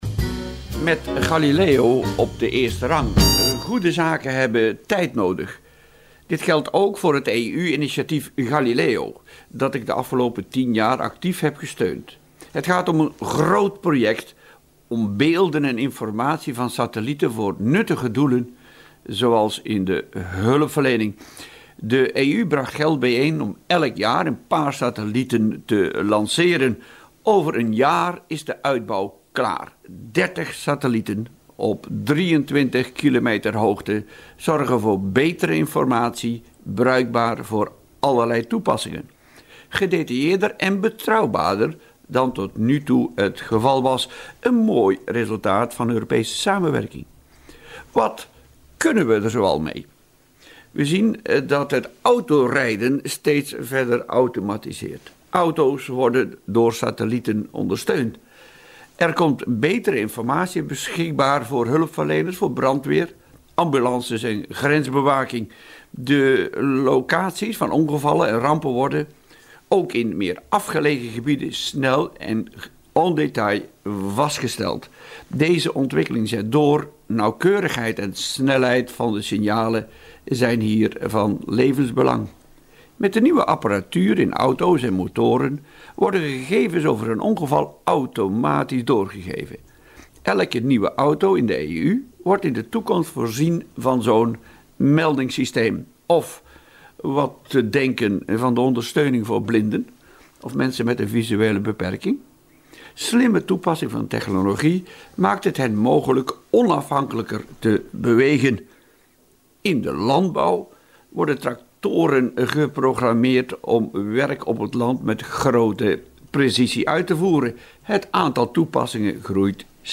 Radiocolumn nummer 98: Met Galileo op de eerste rang